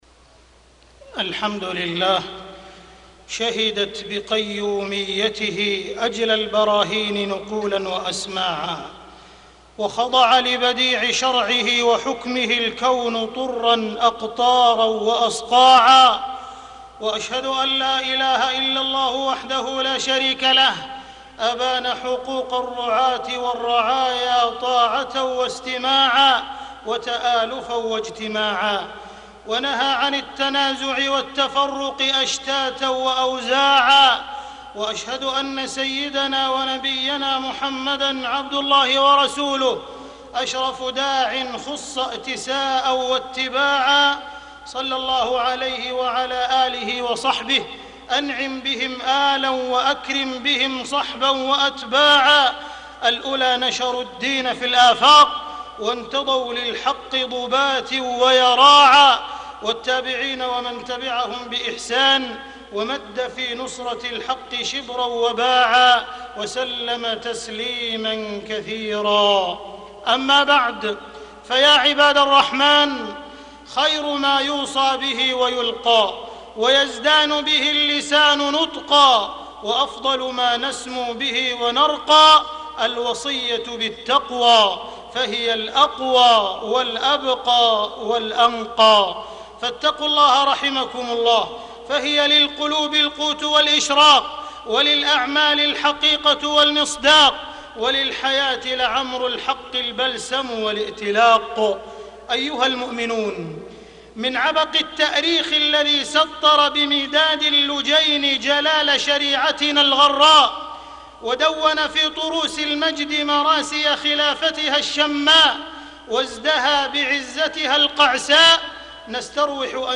تاريخ النشر ٢٣ شوال ١٤٢٦ هـ المكان: المسجد الحرام الشيخ: معالي الشيخ أ.د. عبدالرحمن بن عبدالعزيز السديس معالي الشيخ أ.د. عبدالرحمن بن عبدالعزيز السديس العلاقة الشرعية بين الرعاة والرعية